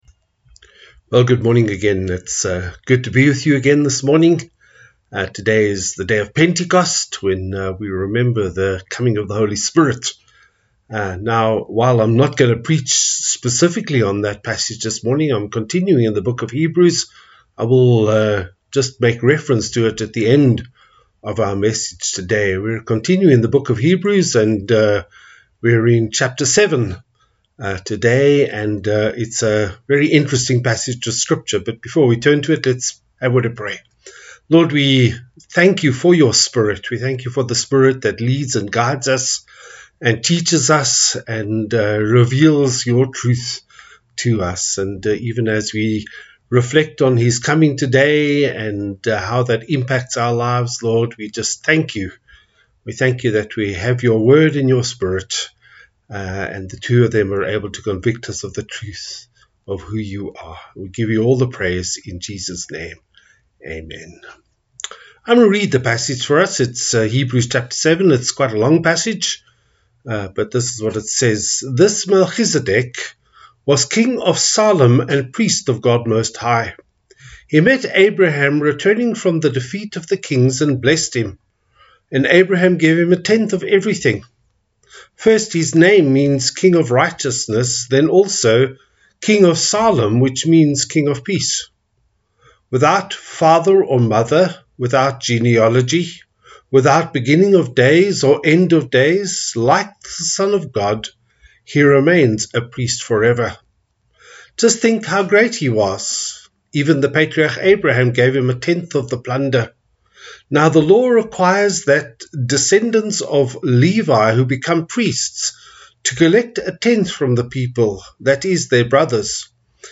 Passage: Hebrews 7:1-28 Service Type: Sunday Service